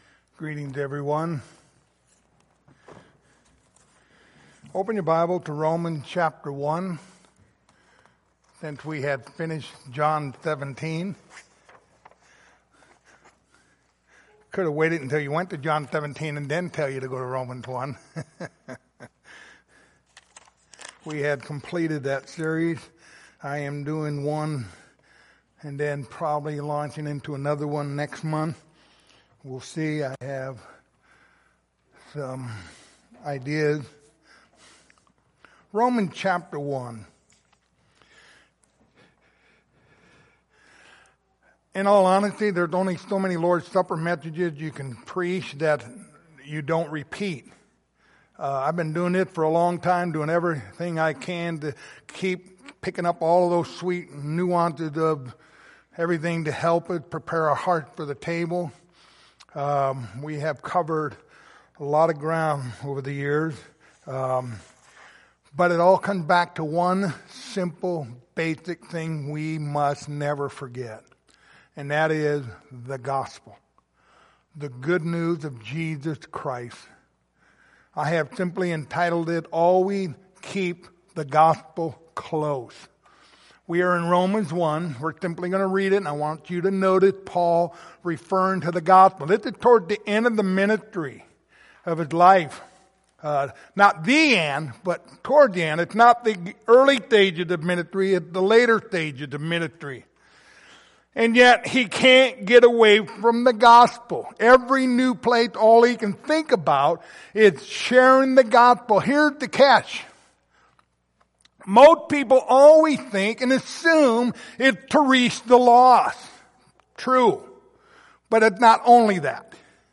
Passage: Romans 1:1-16 Service Type: Lord's Supper